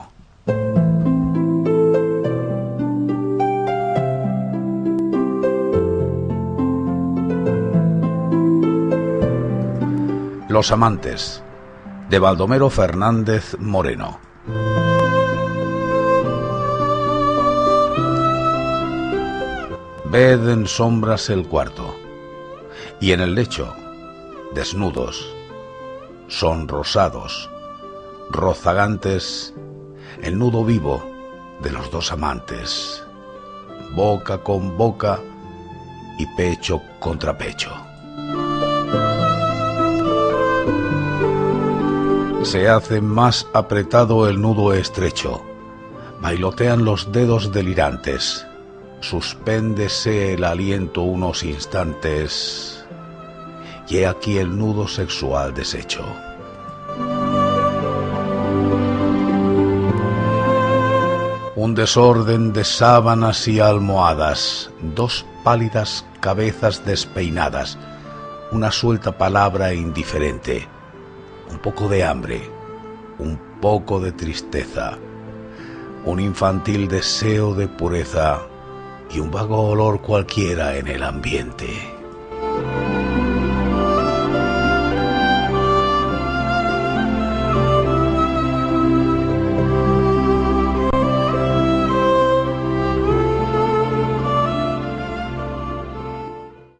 Читает